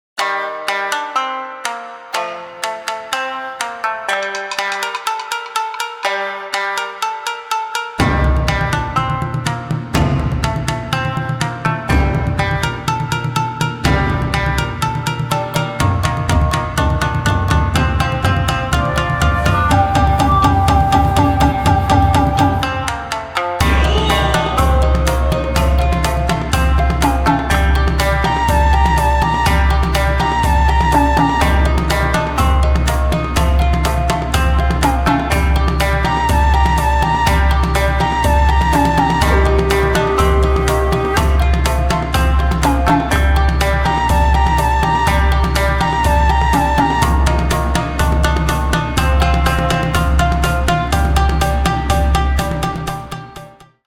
Traditional Japanese version